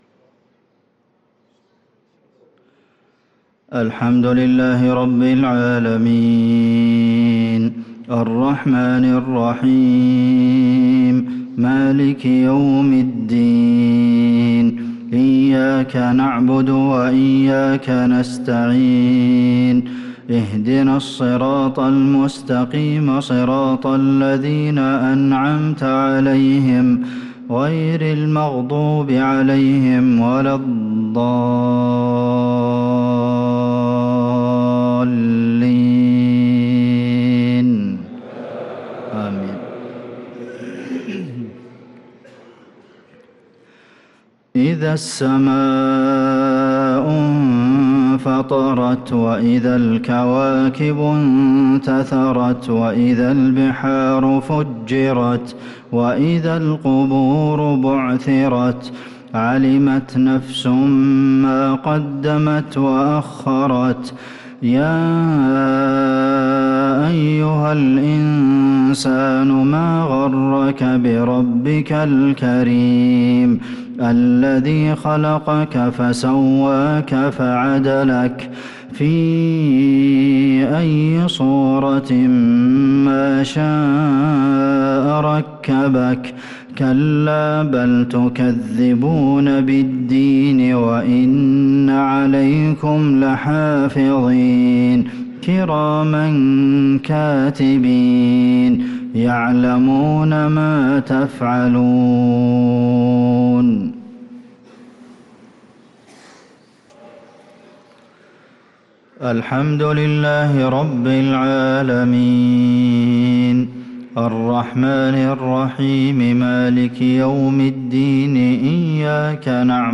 صلاة المغرب للقارئ عبدالمحسن القاسم 13 شعبان 1445 هـ
تِلَاوَات الْحَرَمَيْن .